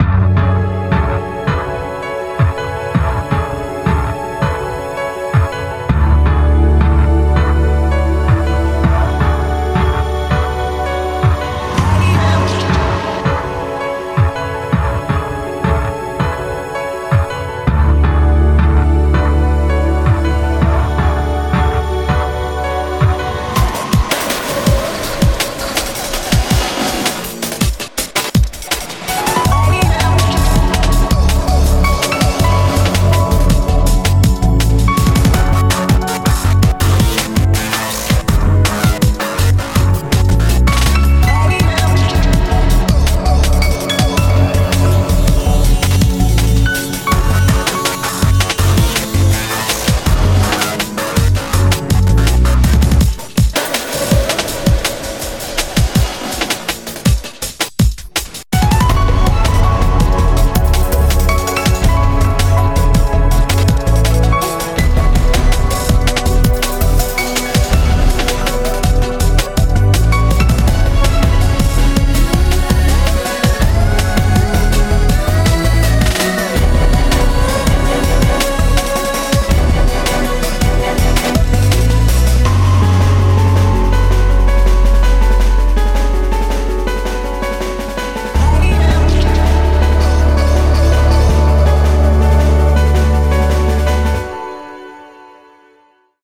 BPM82-163
Audio QualityPerfect (High Quality)
It's IDM, so expect some odd tricks.